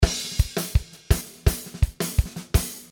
167 BPM - I Need Speed (68 variations)
There are 19 tom fills and there are some snare fills all so.
This loop song is chassidic beat style, fast and very energetic.